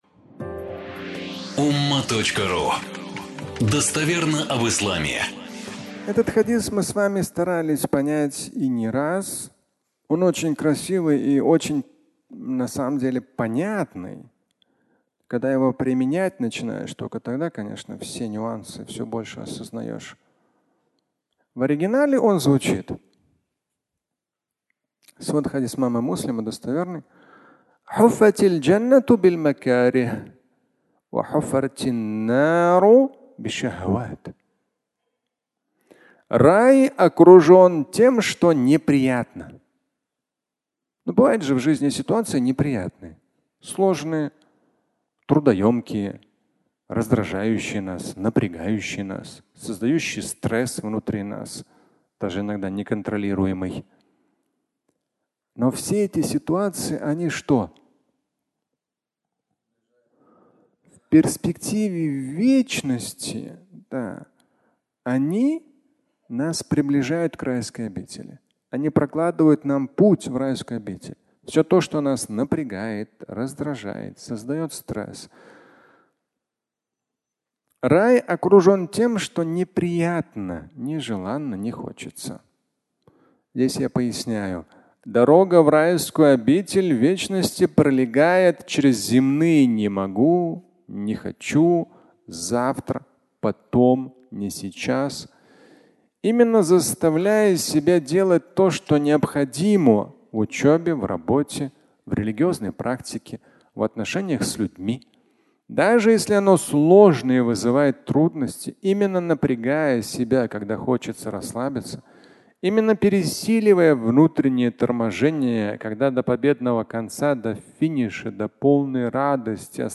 Дорога в Ад (аудиолекция)